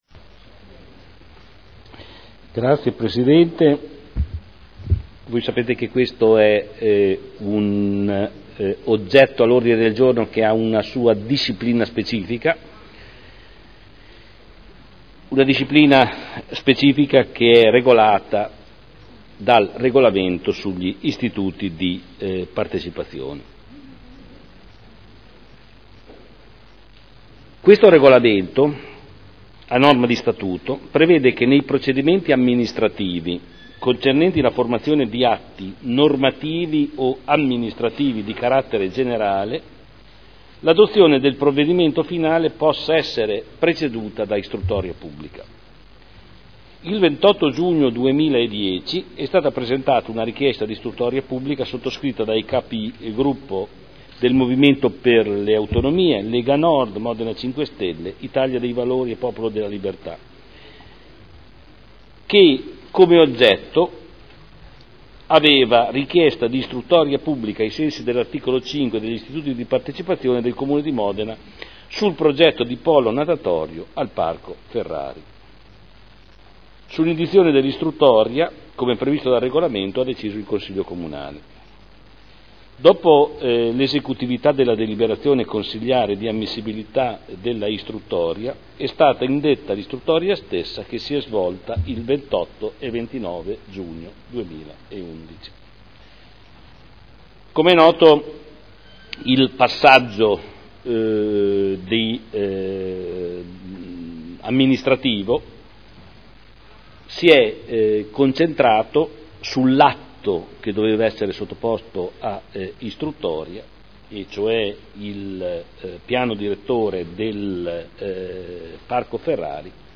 Seduta del 9/07/2012. Istruttoria pubblica Progetto Direttore del Parco Ferrari - Dibattito (art. 7 comma 5 Regolamento comunale sugli istituti di partecipazione)